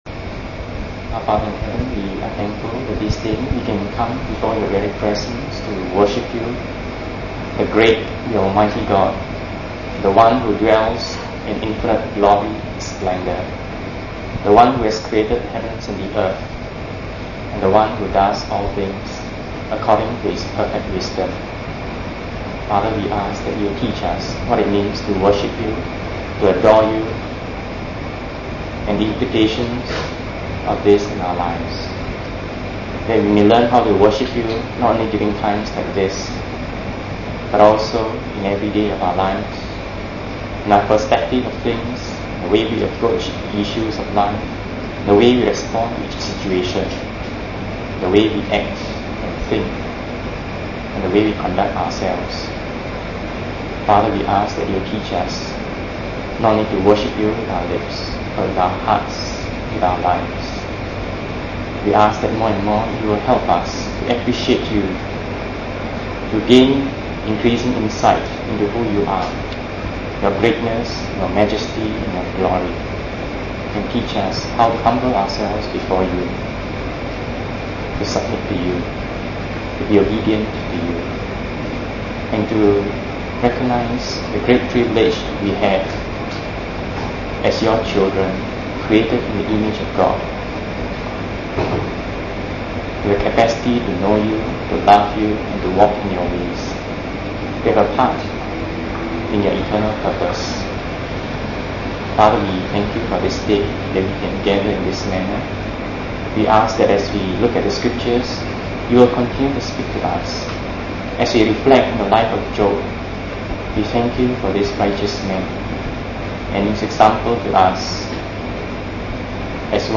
Preached: 5 Oct 86